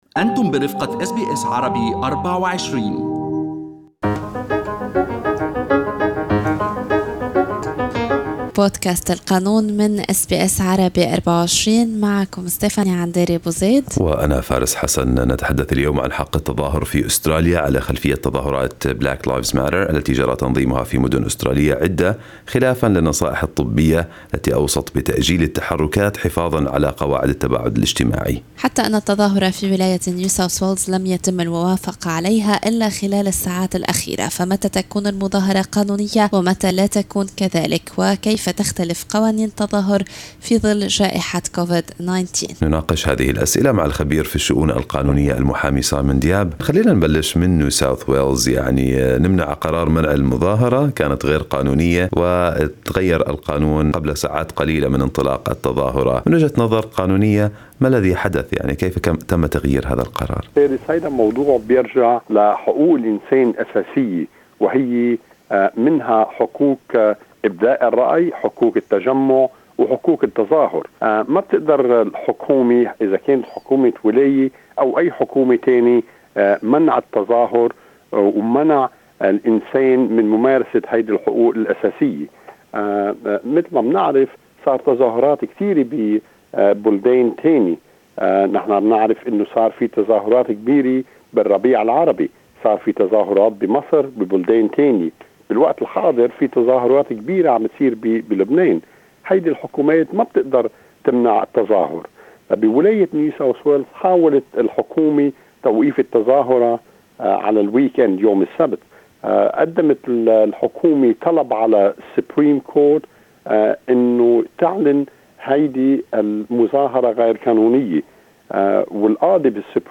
لقاء